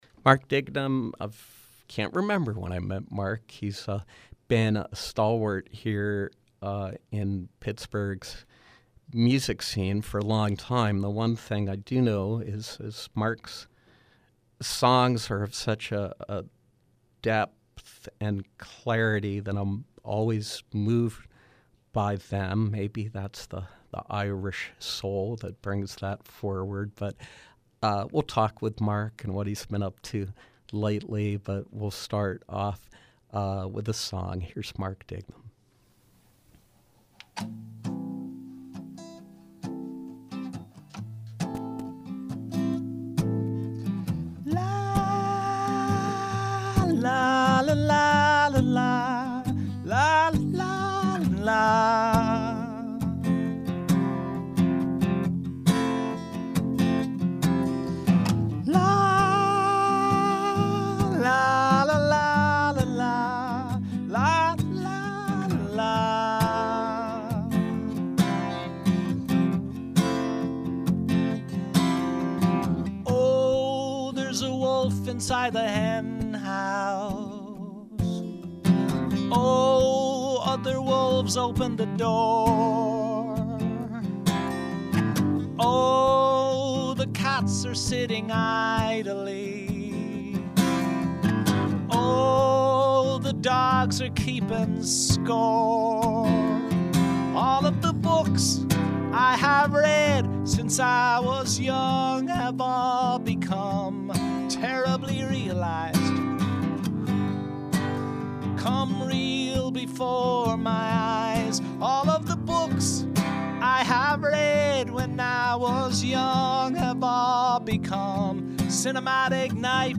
Singer/songwriter